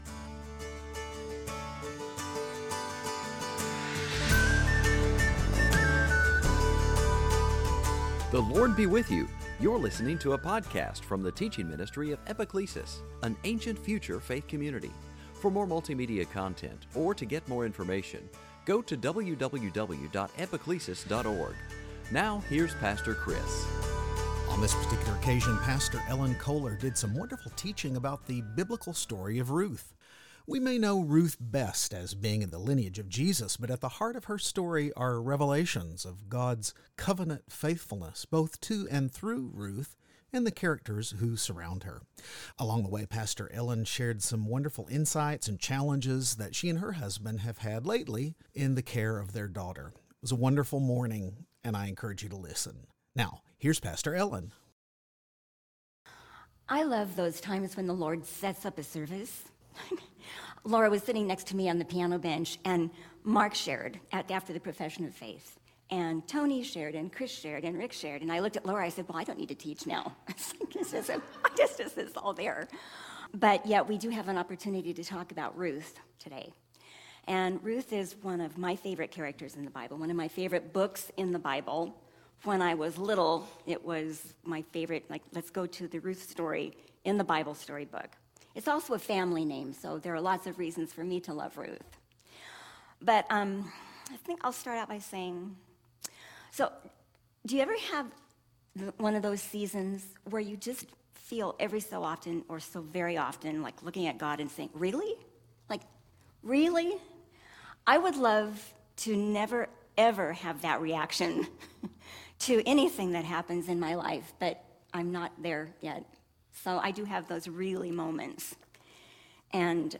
Series: Sunday Teaching